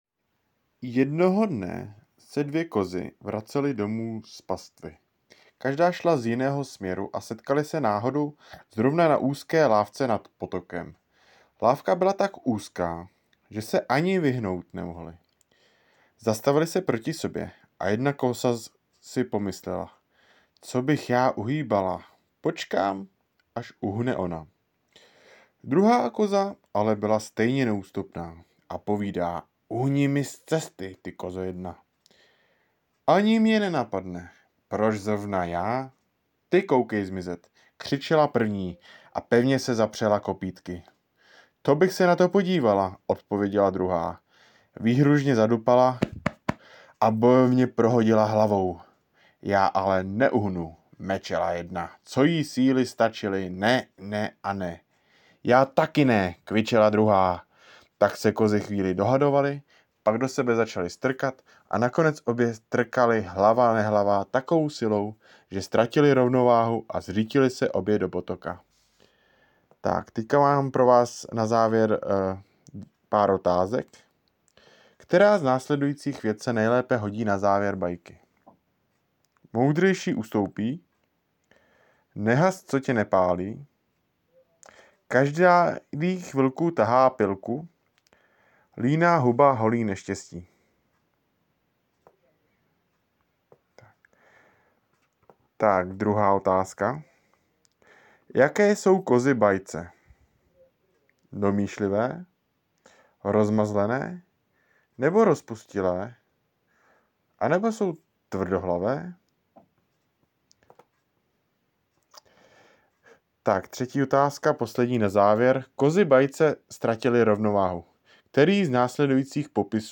Poslech s úkoly: bajka Kozy